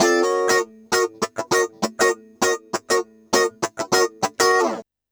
100FUNKY06-L.wav